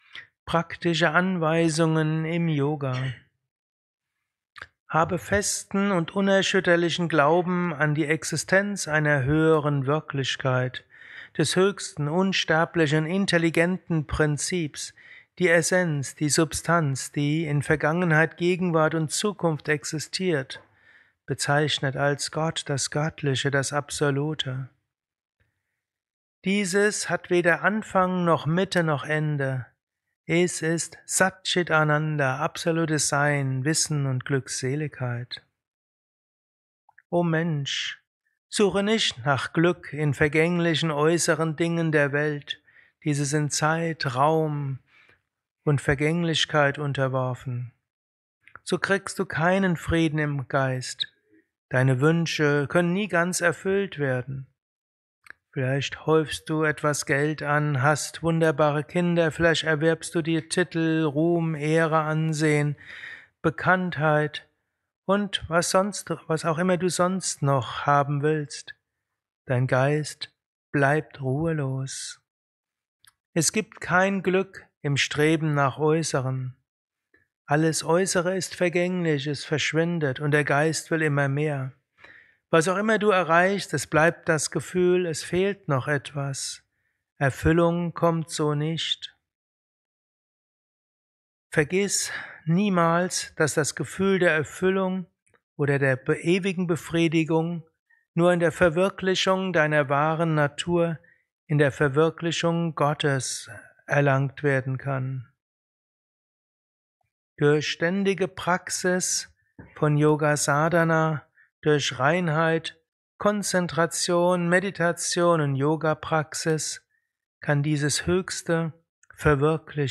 Dies ist ein kurzer Vortrag als Inspiration für den heutigen Tag
während eines Satsangs gehalten nach einer Meditation im Yoga